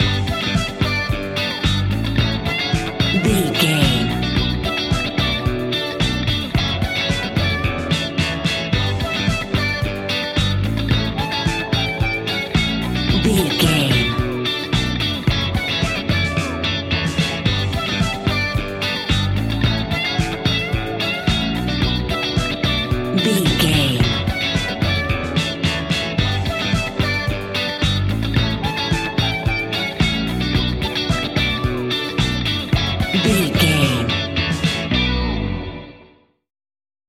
Ionian/Major
D♭
house
synths
techno
trance